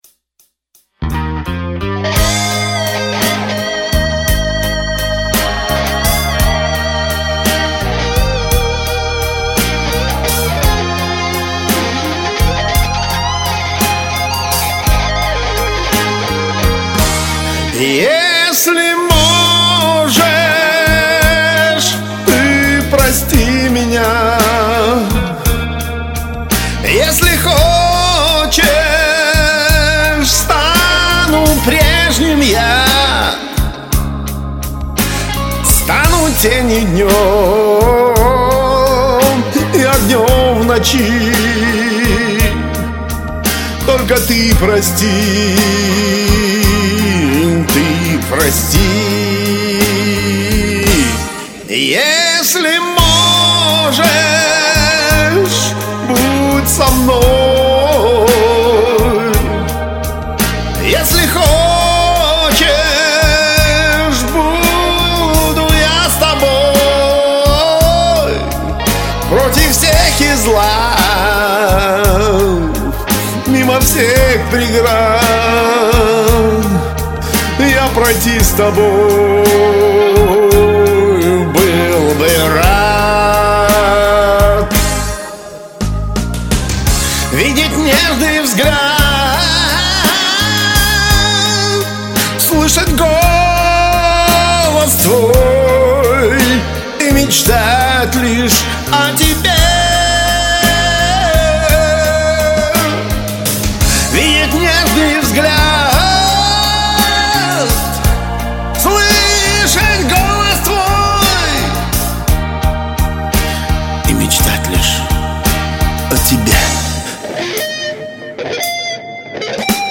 Оба такие певучие ) Сплошная кантилена.